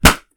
Balloon Pop (from a Pencil)
balloon burst pencil pop sound effect free sound royalty free Sound Effects